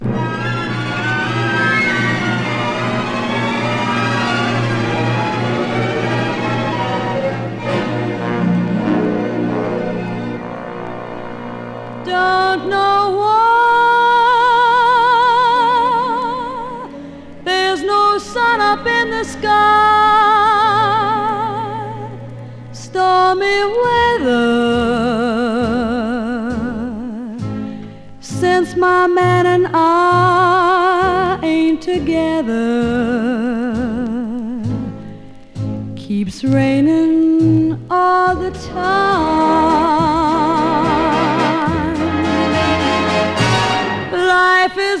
Jazz, Pop, Vocal, Easy Listening　USA　12inchレコード　33rpm　Stereo